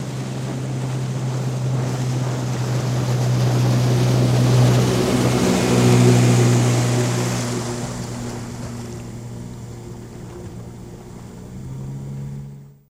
50 hp Motorboat Drives By At Medium Speed